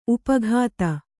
♪ upa ghāta